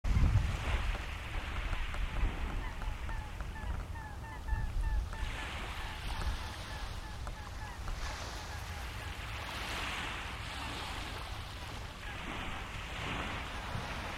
Mergulhao-grande o chorona (Podiceps major)
Nome em Inglês: Great Grebe
Fase da vida: Adulto
Localidade ou área protegida: Puerto Madryn
Condição: Selvagem
Certeza: Observado, Gravado Vocal